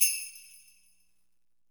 Tm8_HatxPerc55.wav